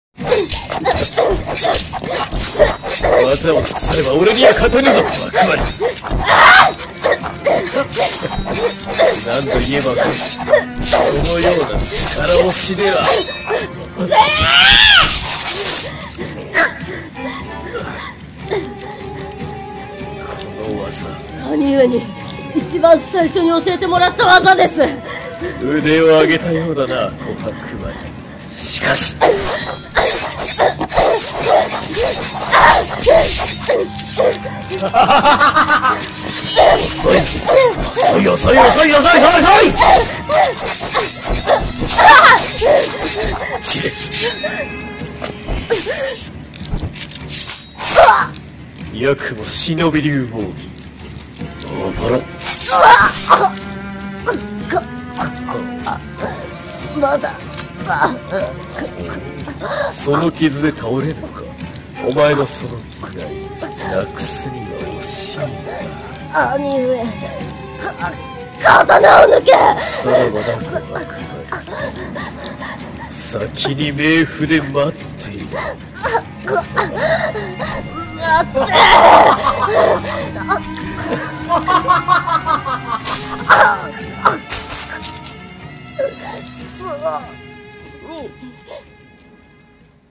【和風声劇】